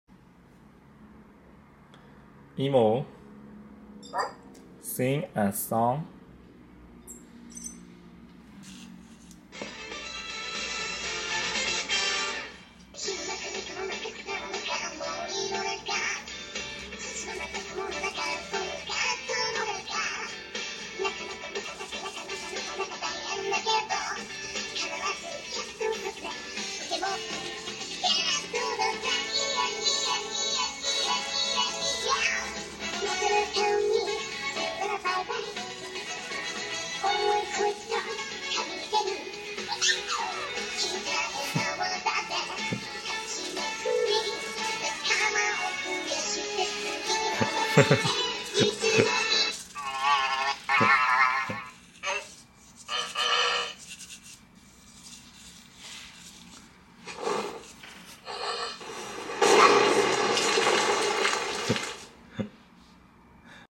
EMO sings a song. But sound effects free download